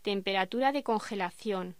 Locución: Temperatura de congelación
voz